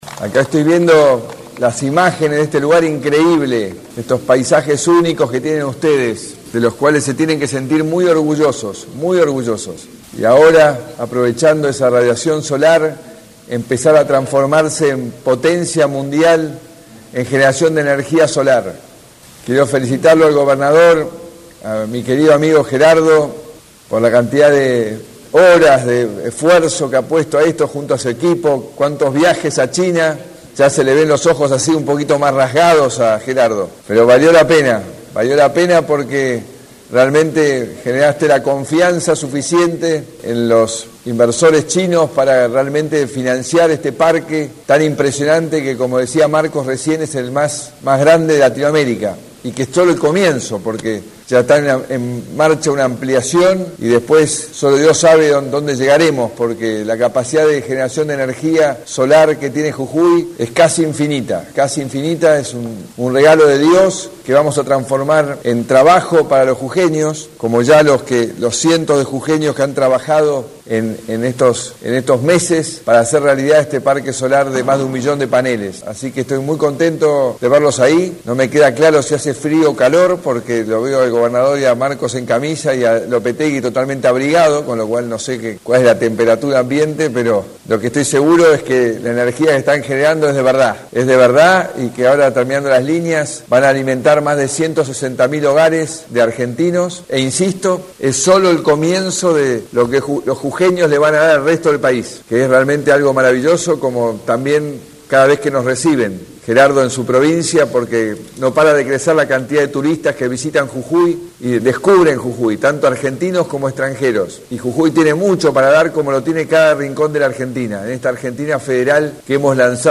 El presidente Mauricio Macri estuvo presente mediante videoconferencia en la inauguración de la Planta Fotovoltaica Cauchari, de esa manera felicito al gobierno  y a todo el pueblo jujeño por el inicio del proceso de prueba de la planta.
01-MAURICIO-MACRI-Discurso-en-la-Inauguracion-de-Cauchari.mp3